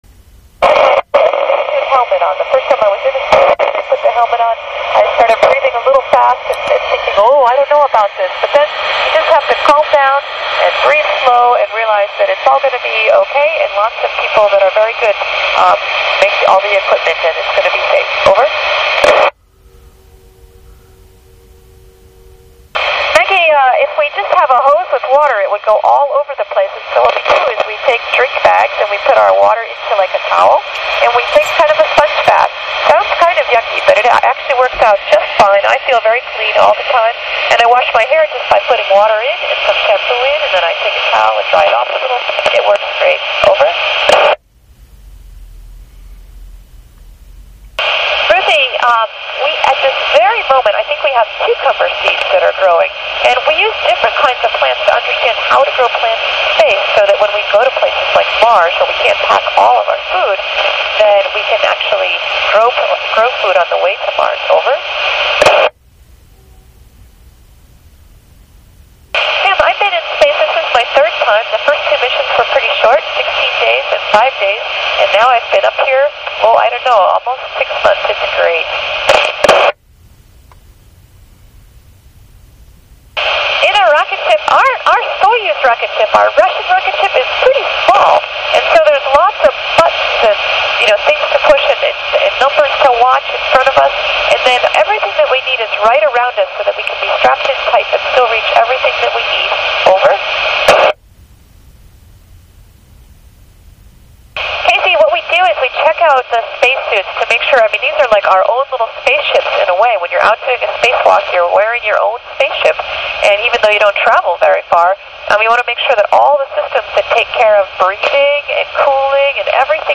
Dr. Cady Coleman Speaks to Students of St. Michael School in Schererville, Indiana. May 9, 2011 18:00 UTC